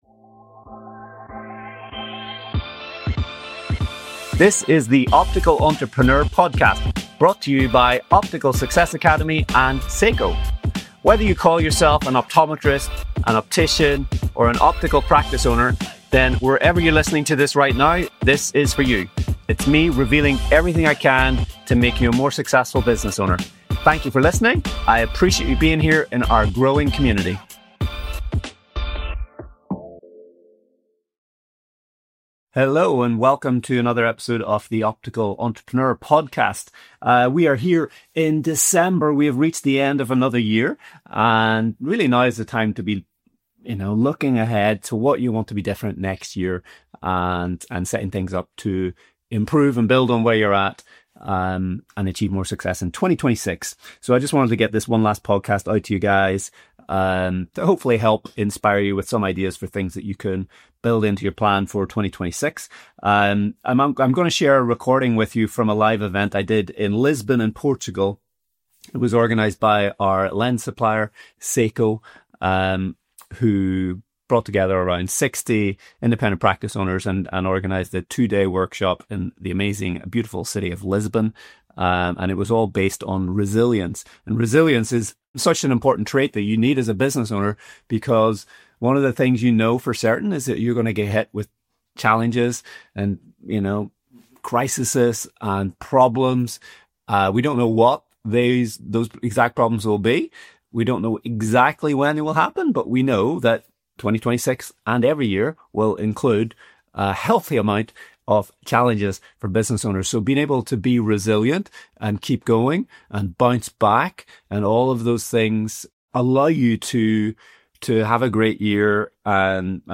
World class interviews with best-selling authors and experts on the subject of entrepreneurship, leadership, business success and personal development.